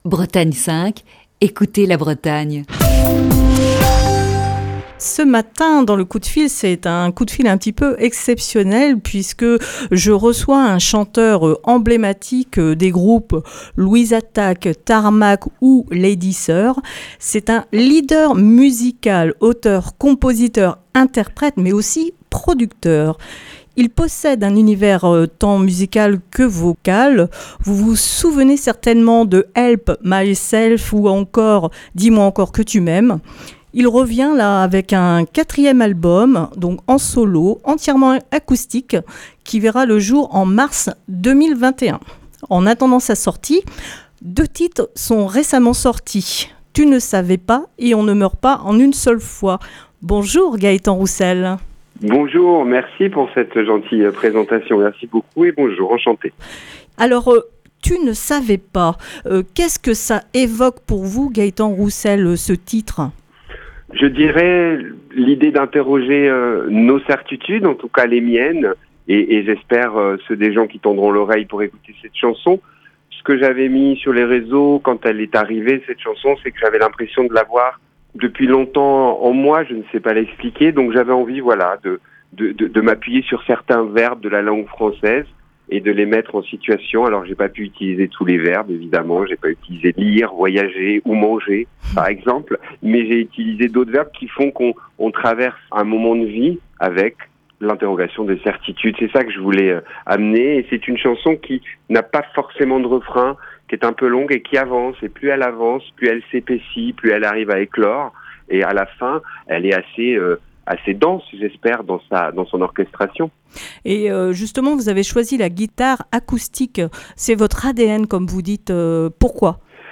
Le coup de fil du matin raccroche pendant les fêtes.